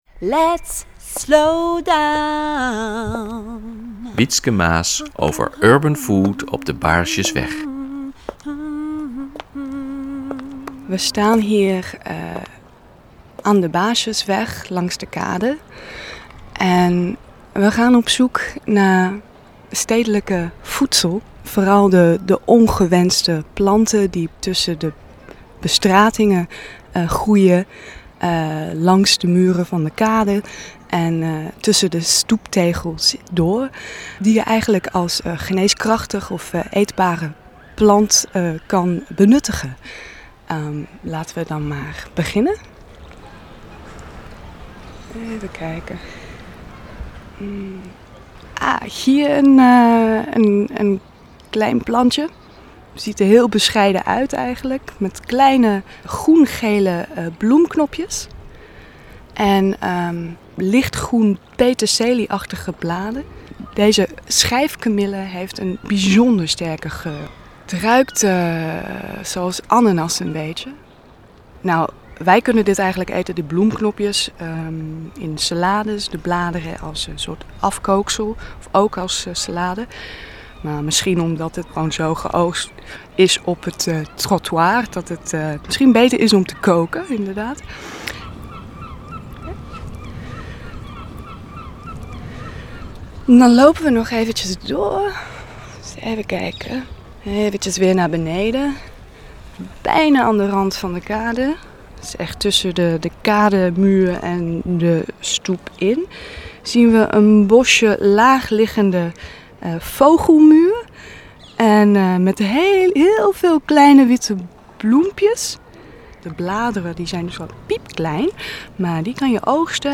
recorded-interview stroll (in Dutch) between de Baarsjesweg and the Kostverlorenvaart, one of Amsterdam’s busiest canals.